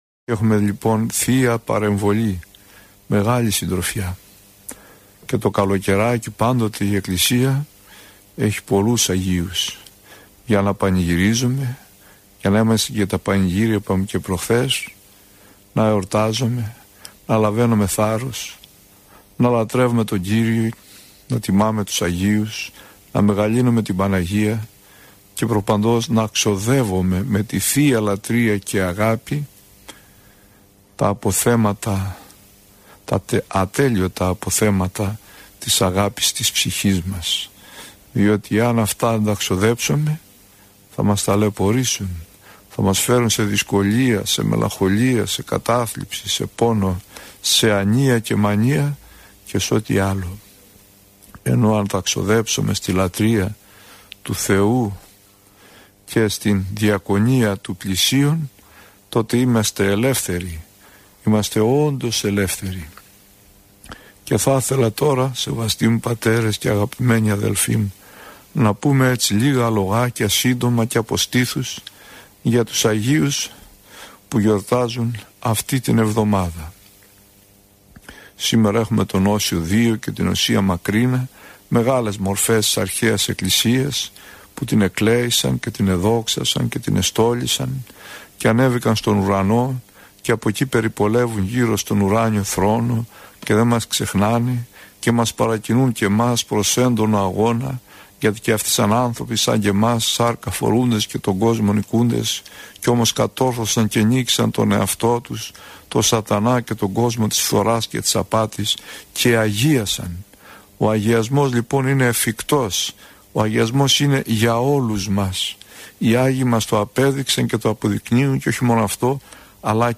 Πρόκειται για εκπομπή που μεταδόθηκε από τον ραδιοσταθμό της Πειραϊκής Εκκλησίας.